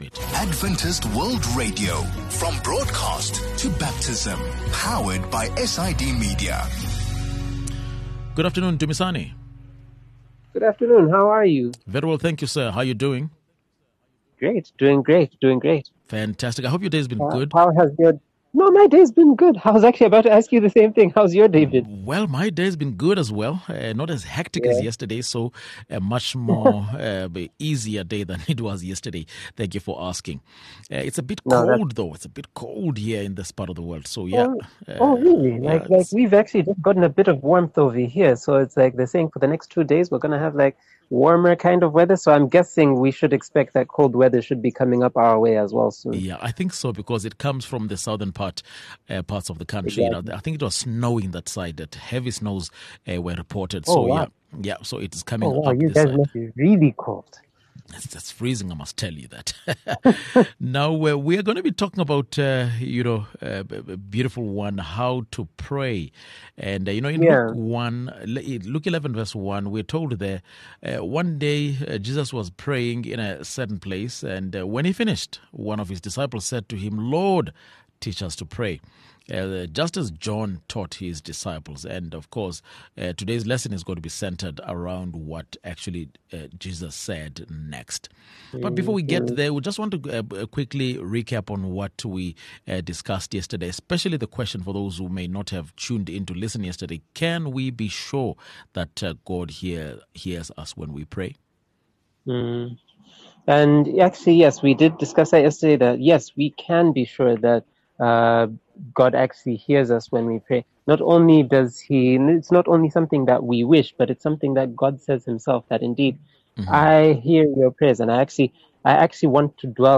4 Jun VOP Lesson | How to Pray